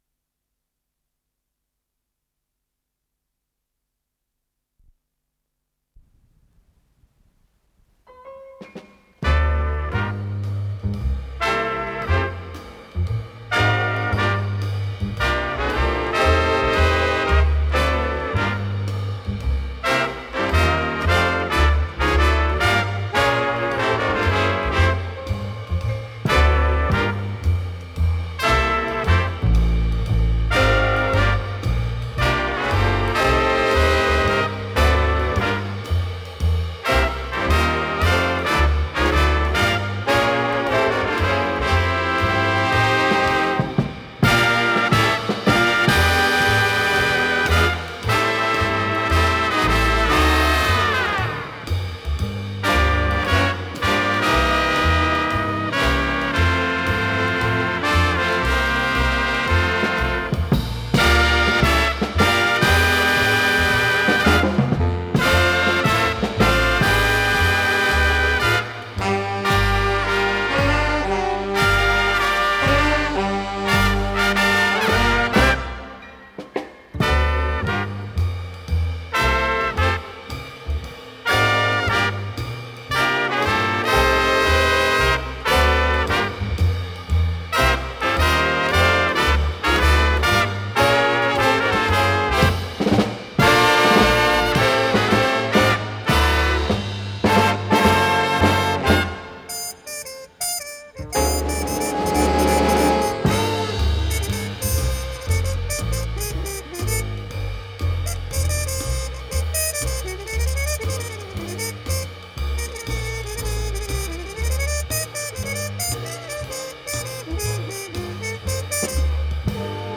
с профессиональной магнитной ленты
труба
саксофон
ВариантМоно